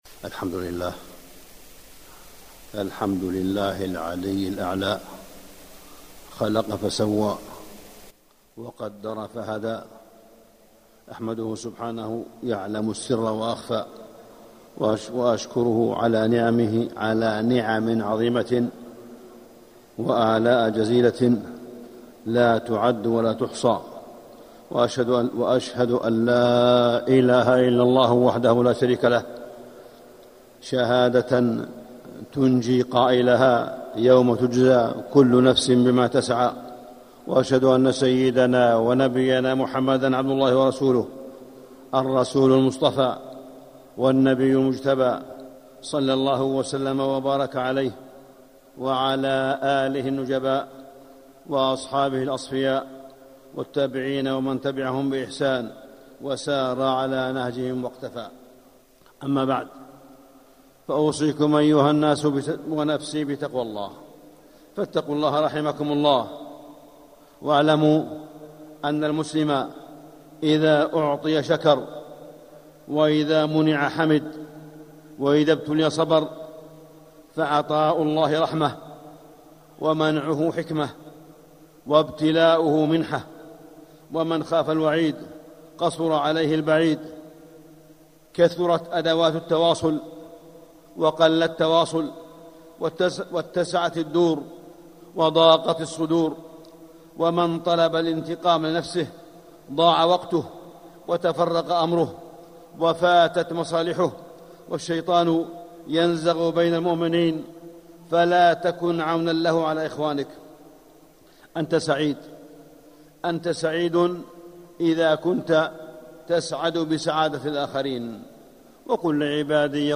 مكة: صلاة الاستخارة - صالح بن عبد الله بن حميد (صوت - جودة عالية. التصنيف: خطب الجمعة